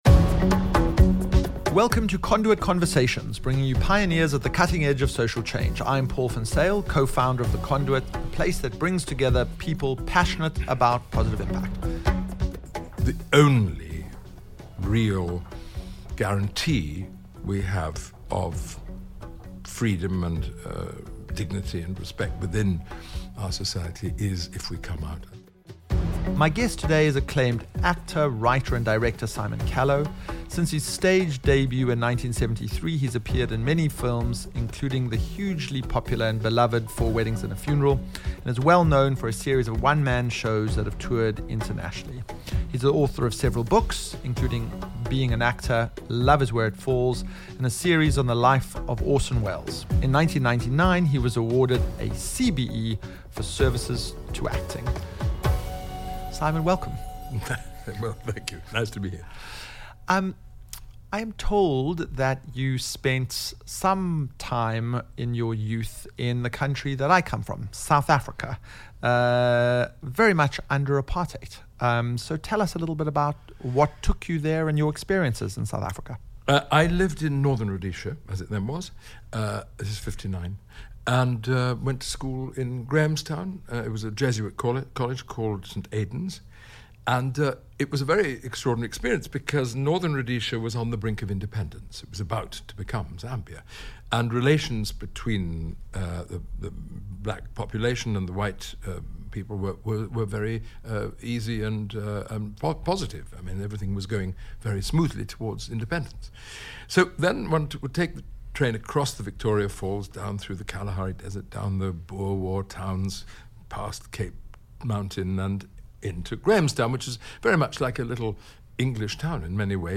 This episode was recorded at The Conduit before Simon's appearance in a special production of 'Whodunnit [Unrehearsed]' in our Speakeasy, presented in partnership with Park Theatre.